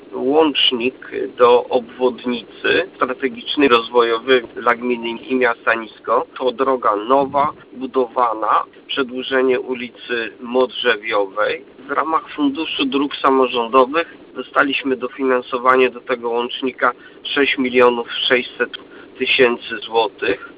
Burmistrz Niska Waldemar Ślusarczyk przyznał, że zarówno obwodnica jak i łącznik do niej to dla Niska strategiczne inwestycje drogowe dające połączenie z budowaną s19 i nowe perspektywy rozwoju.